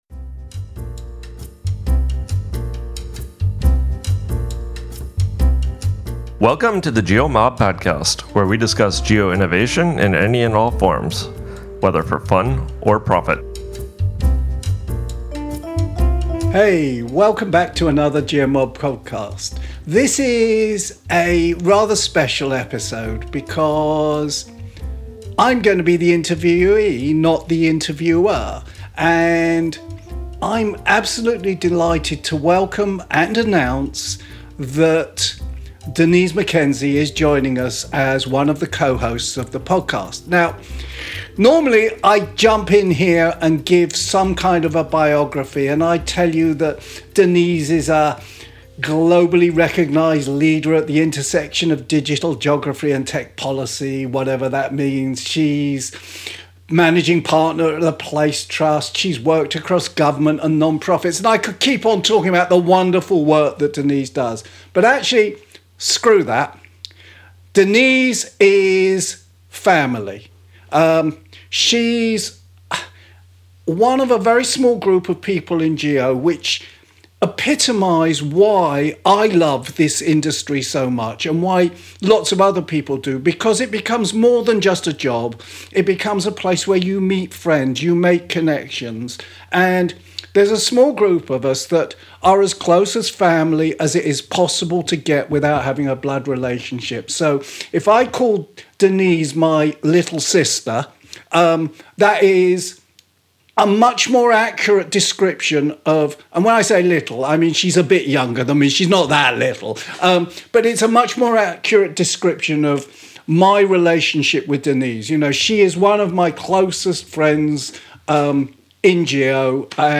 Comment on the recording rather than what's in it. Our Discover Guides are deep dives into new destinations, where we interview experts to get the ‘drum’ on the places they live and love. Today we’re excited to share the first of two episodes exploring South Australia, a less-trodden state rich in adventure, wildlife and natural beauty.